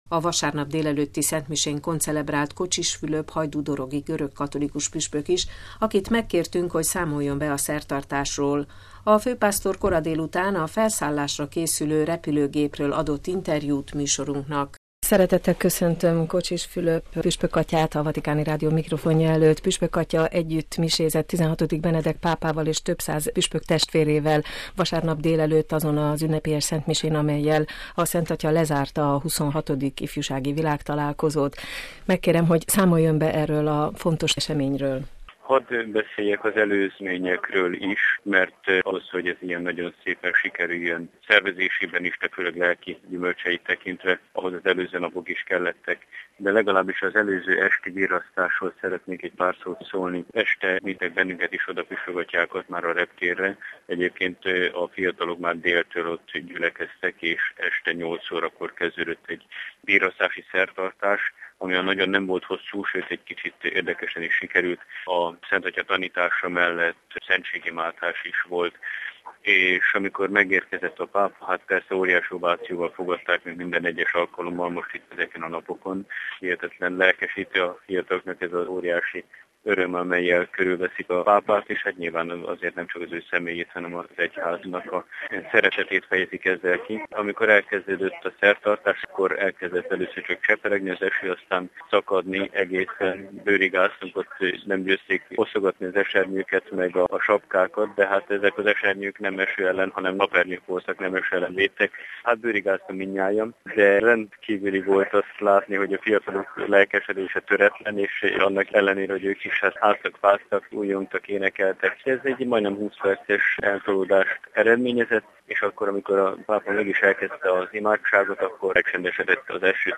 Feladatunk, hogy továbbadjuk a hitnek azt a nagy erejét, amelyet Madridban megtapasztalhattunk – mutat rá többek között interjújában Kocsis Fülöp püspök.
A főpásztor vasárnap koradélután, a felszállásra készülő repülőgépről nyilatkozott műsorunknak.